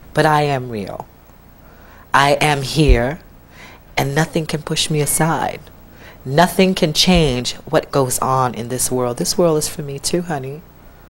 The changing of pitch of this recording, starting with the same pattern of distortion, relates to the changes of voice that occur during the process of transition, as well as serves as a recognition of trans musical artists who edit their voice’s pitch, today mainly seen in the hyperpop genre.